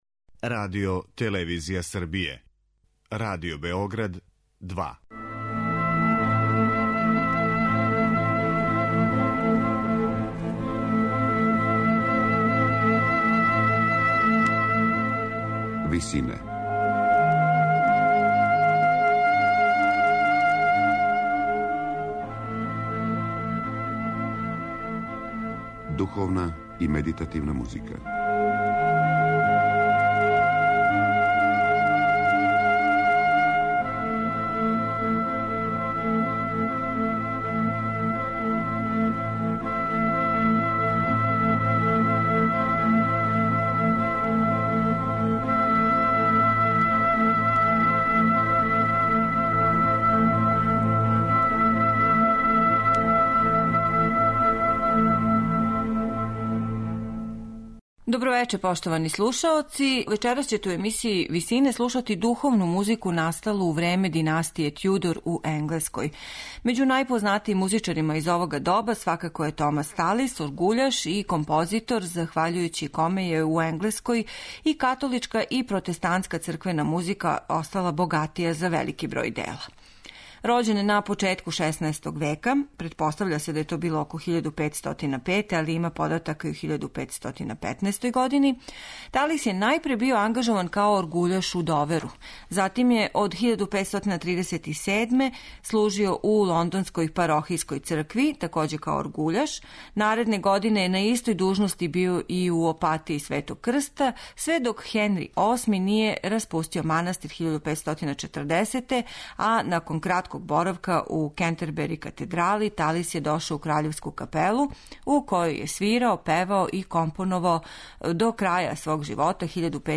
у ВИСИНАМА представљамо медитативне и духовне композиције аутора свих конфесија и епоха.
Слушаћете избор из његовог разноврсног вокалног опуса писаног на латинском и енглеском језику, у извођењу ансамбала The Tallis Scholars и Taverner Consort .